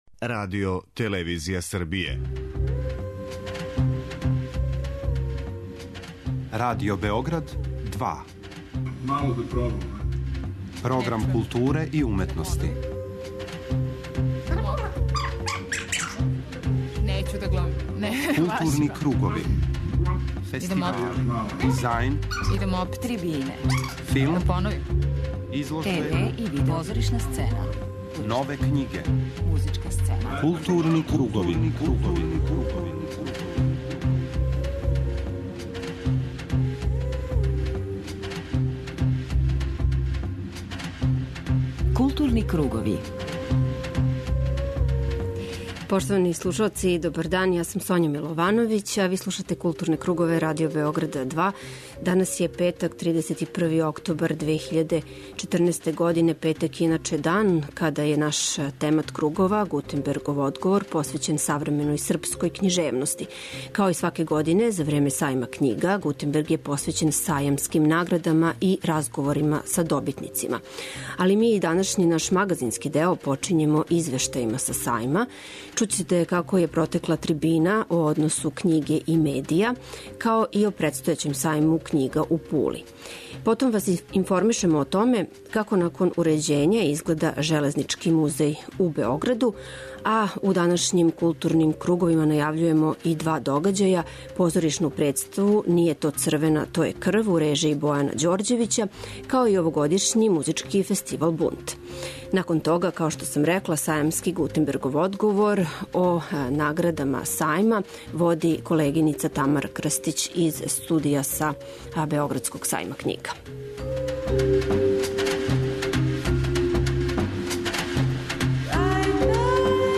Данашњи 'Културни кругови', са тематом 'Гутенбергов одговор', емитује се са 59. Међународног сајма књига.
Додељене су награде за издавача године, издавачки подухват године, најбољу дечју књигу и најлепшу књигу... Чућете разговоре са награђеним издавачима и ауторима на овогодишњем Сајму књига.